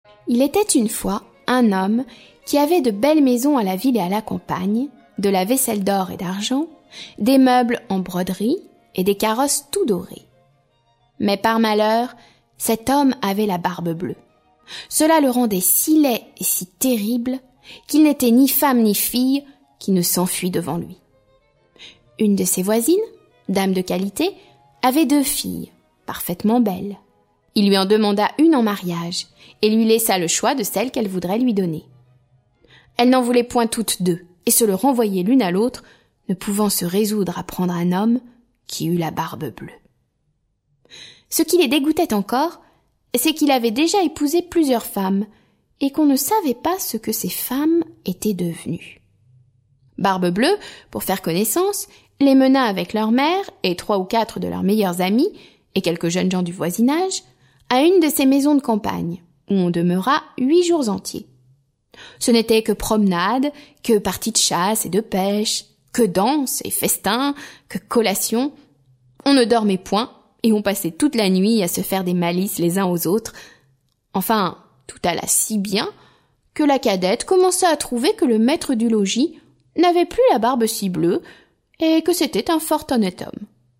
Musique : Bach (4ème concerto brandebourgeois) et Beethoven (Egmont, ouverture)